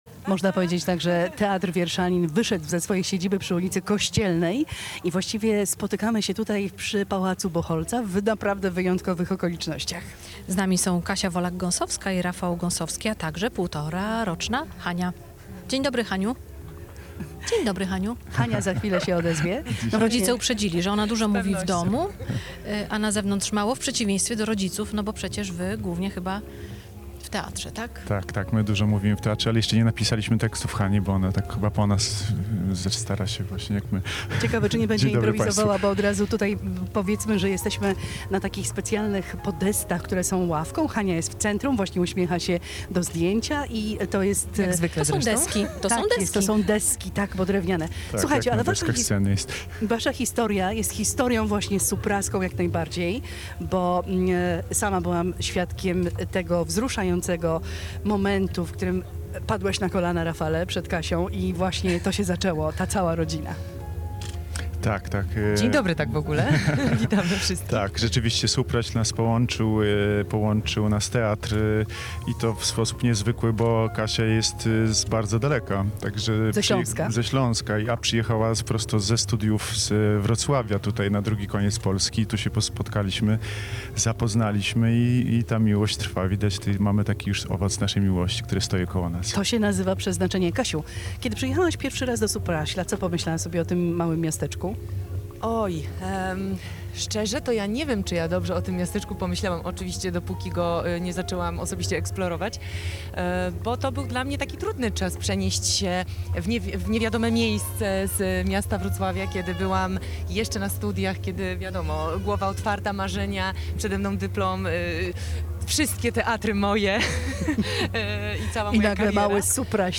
Rozmowa
Z naszego plenerowego studia ustawionego przed Pałacem Buchholtzów zachęcaliśmy do spacerowania po miasteczku i relaksu w okolicznych lasach.